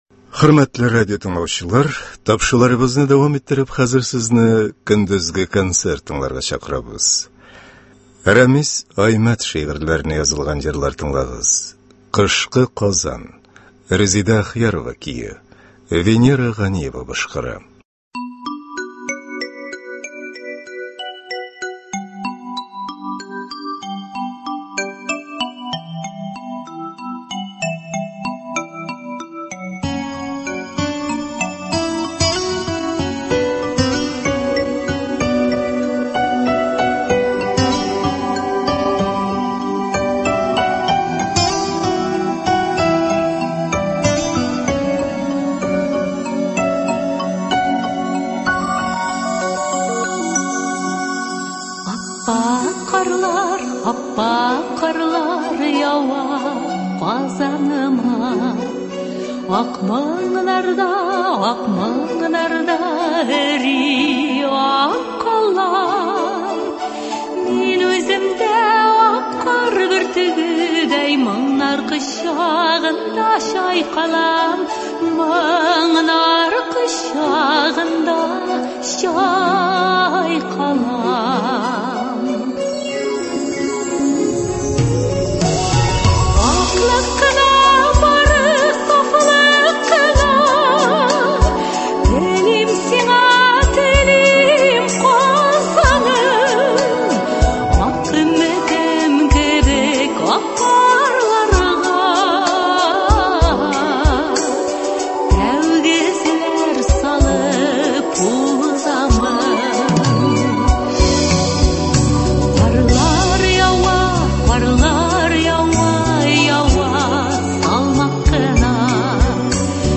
Халык музыка уен коралларында башкаручылар концерты.